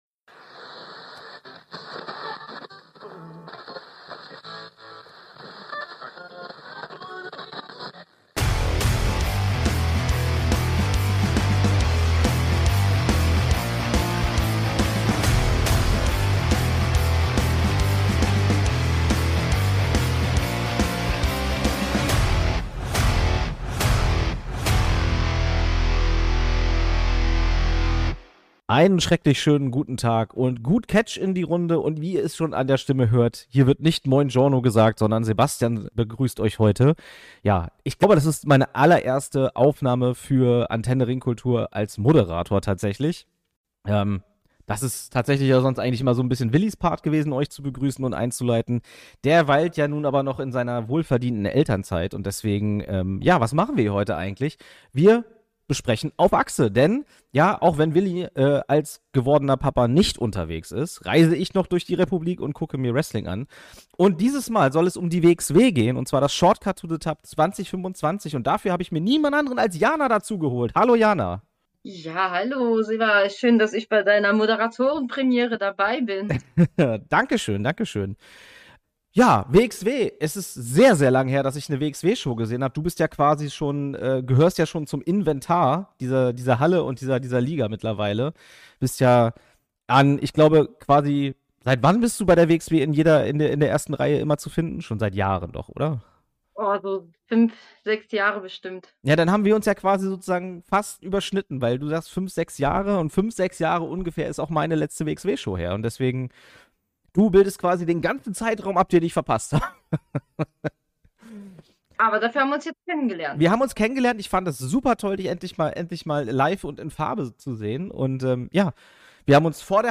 Bitte entschuldigt vereinzelte Tonprobleme.